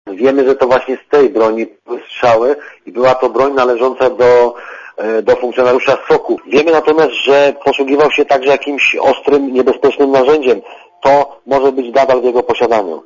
Komentarz audio (50Kb)